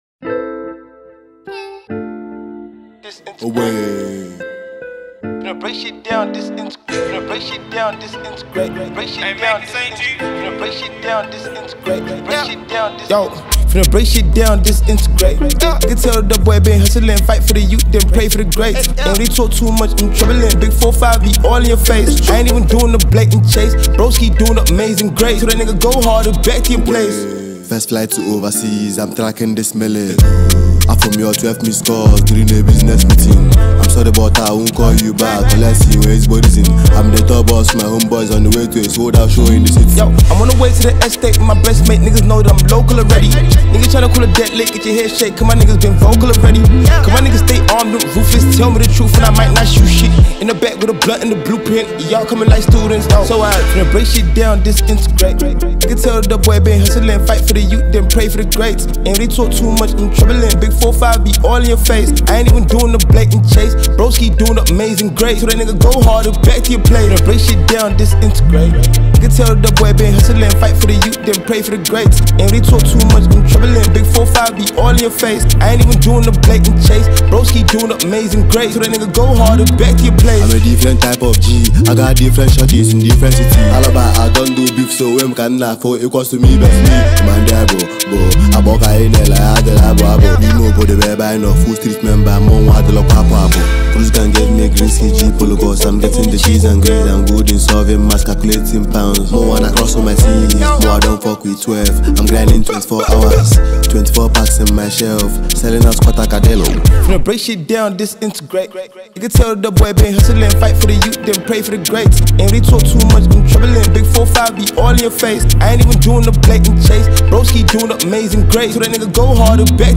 a Nigerian rapper, singer, and songwriter